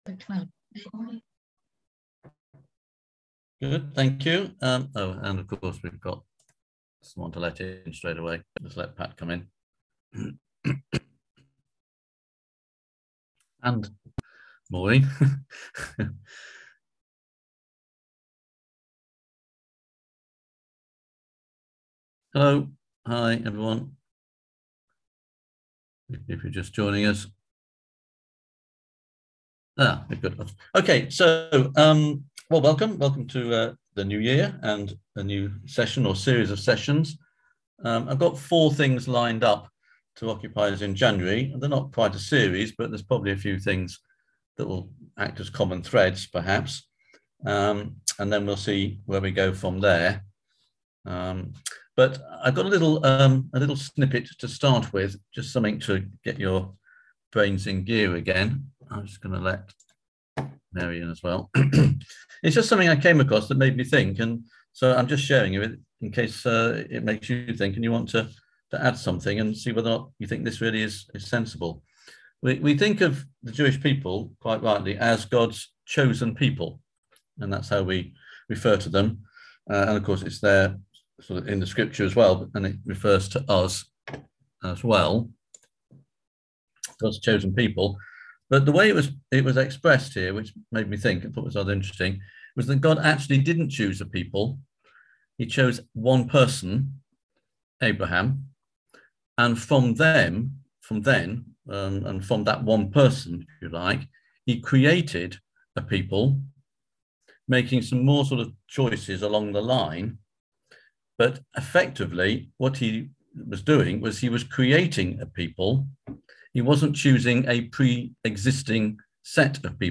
On January 6th at 7pm – 8:30pm on ZOOM